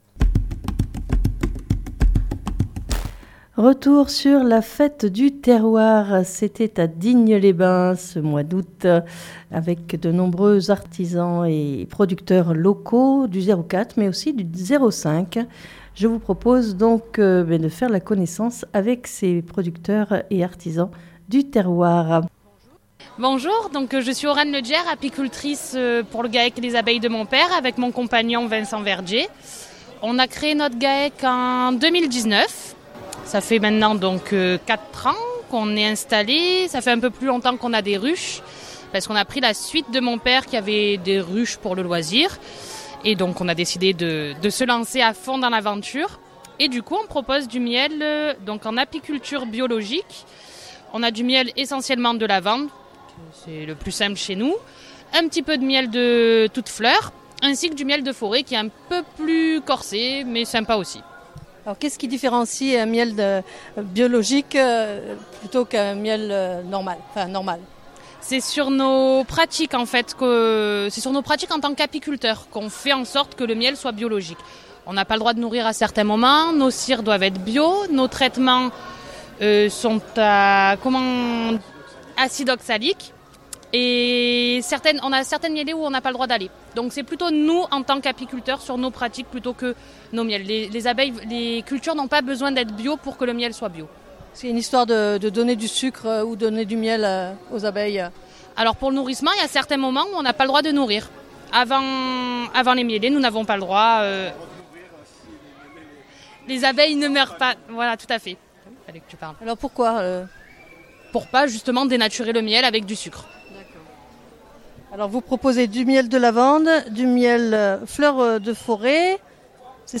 lors de la 24ème Fête du Terroir organisée par la FDSEA 04 à Digne les bains